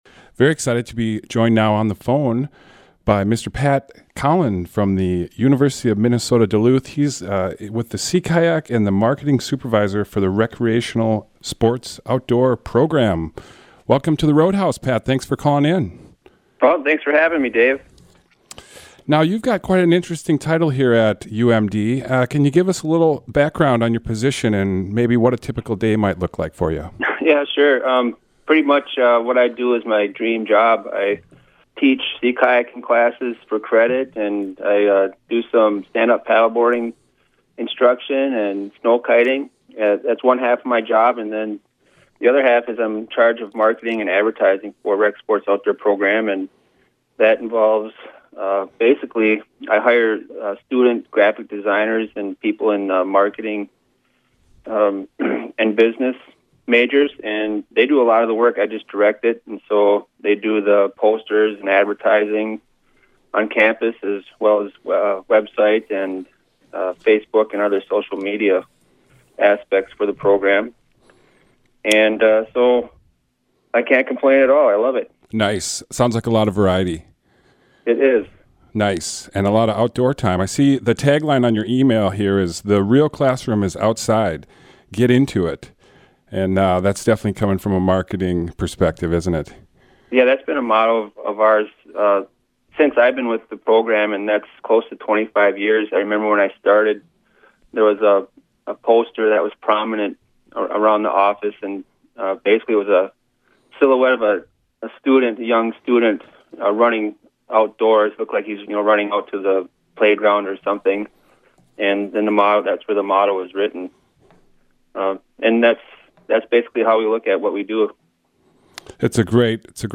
This conversation is part of our effort to gather ideas and stories about environmental sustainability along the North Shore. Program: The Roadhouse